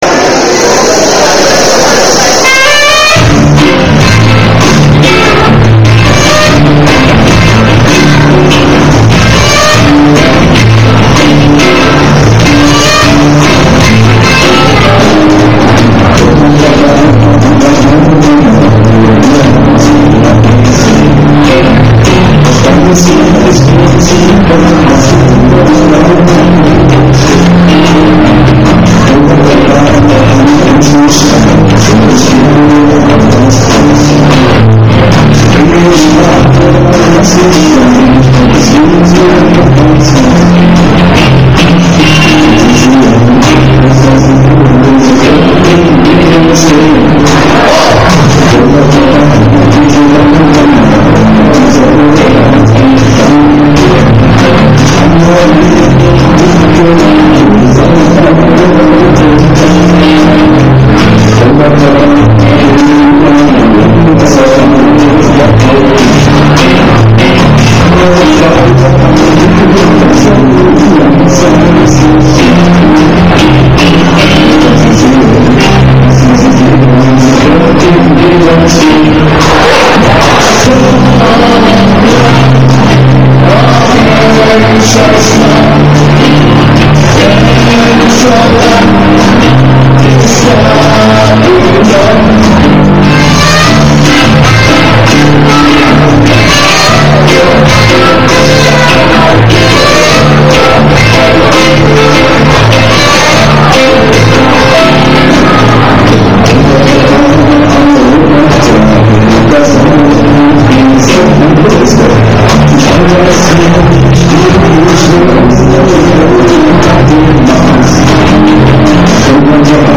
Ebertbad, 31.10.2007
Über 500 zahlende Zuschauer waren begeistert !